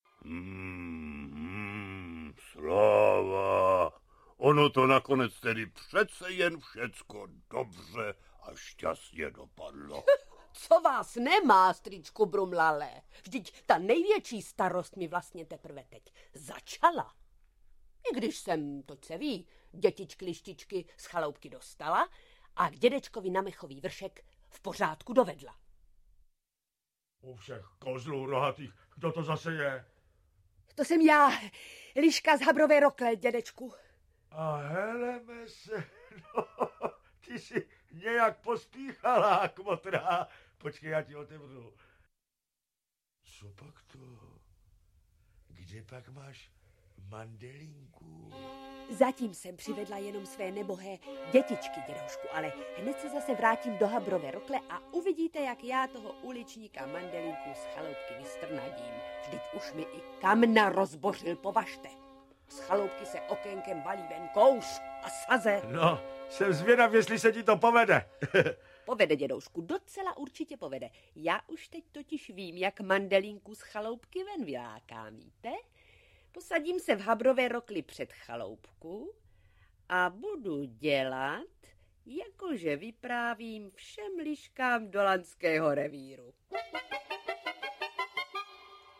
O Budulínkovi a Mandelince audiokniha
Ukázka z knihy
V této dramatizaci z roku 1977 převyprávěl Josef Lada známou pohádku o Budulínkovi poněkud jinak. Budulínek si málo dělá z toho, že ho unesla liška a s malými liščaty v doupěti vyvádí tak, že se ho liška chce rychle zbavit.